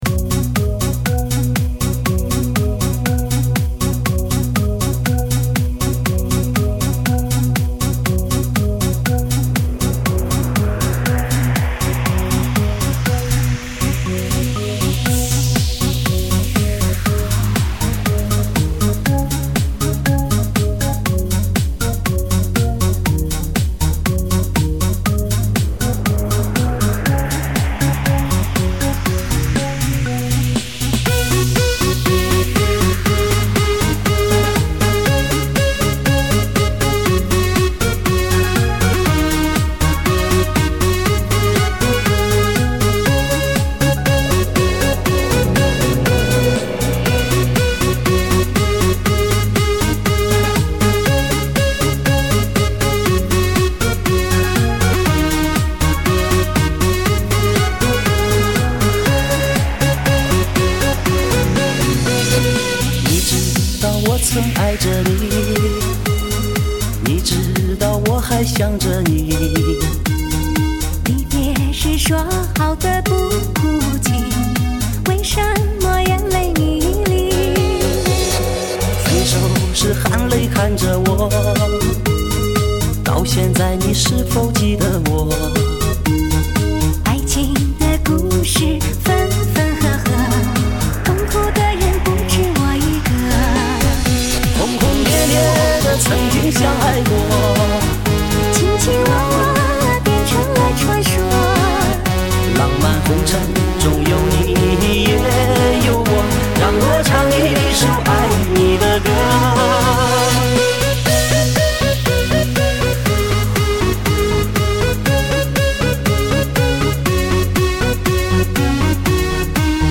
流行中文的士高